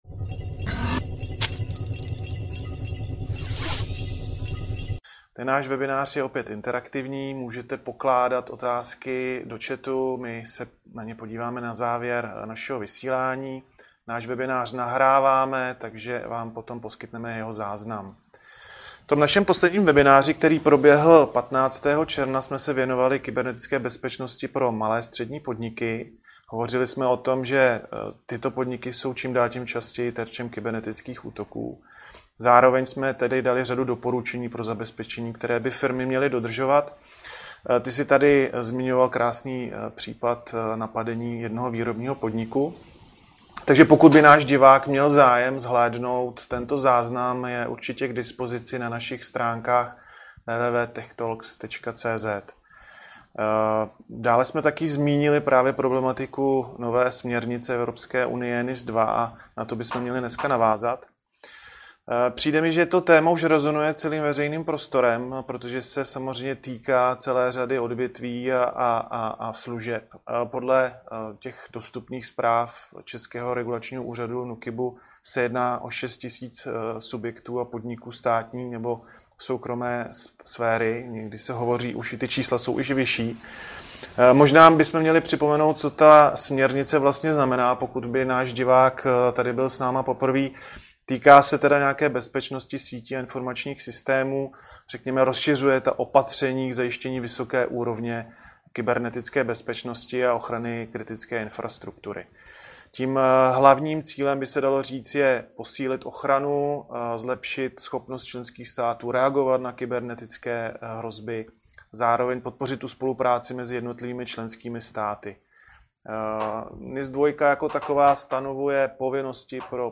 Webinář: Digitalizujte svou bezpečnou budoucnost s CRA - CRA Tech talks